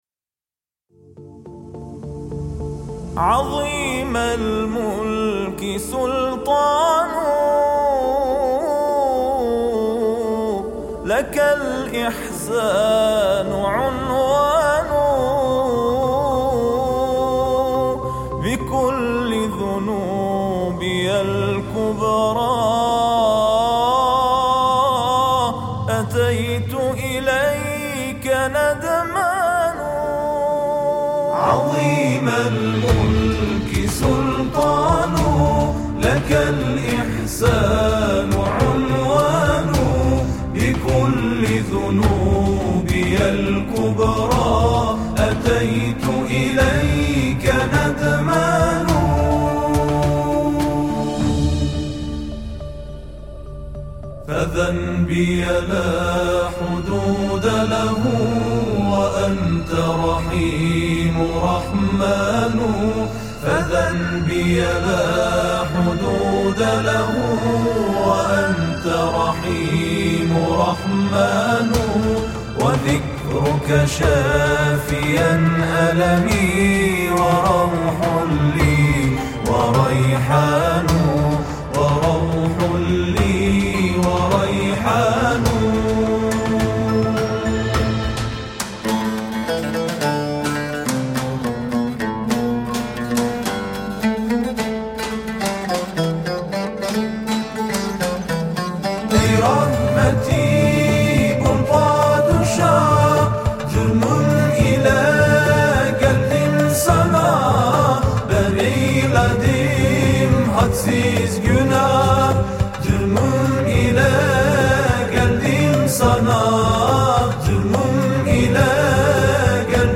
تواشیح خوانی